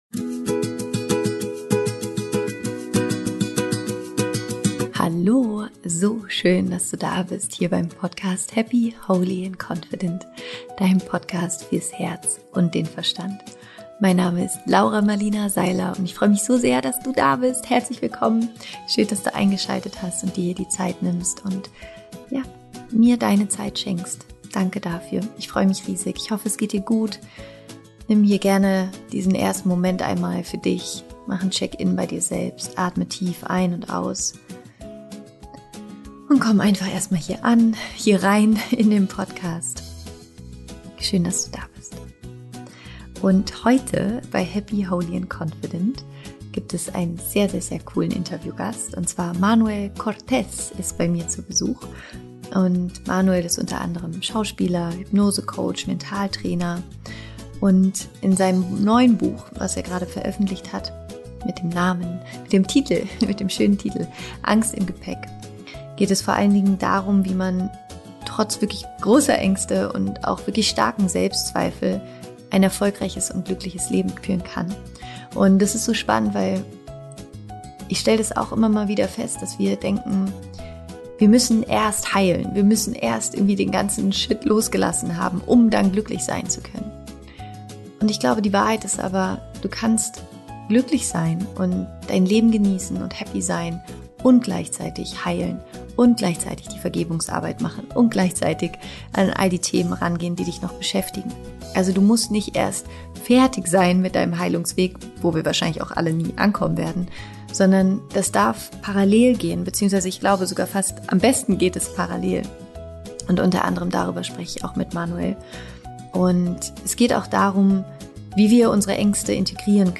Warum Angst nicht dein Feind ist - Interview mit Manuel Cortez